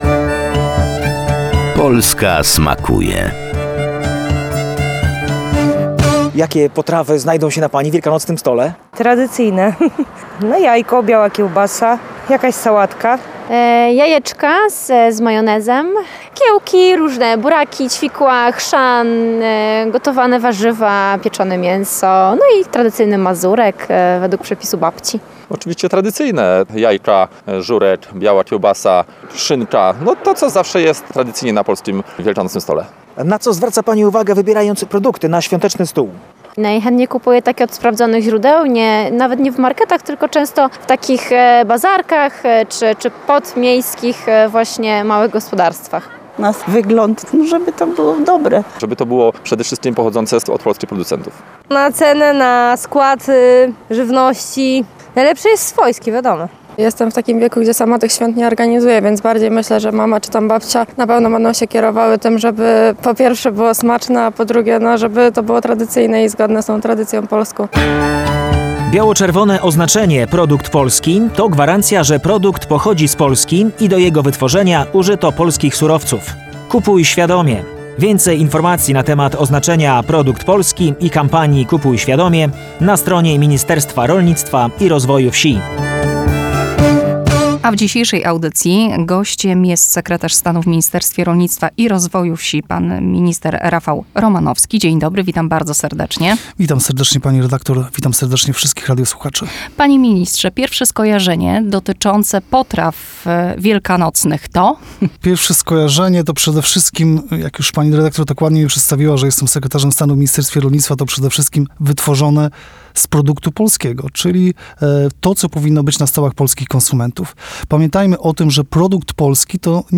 O wielkanocnych smakach w kolejnej audycji z cyklu „Polska smakuje” opowiadał Rafał Romanowski – Sekretarz Stanu w Ministerstwie Rolnictwa i Rozwoju Wsi.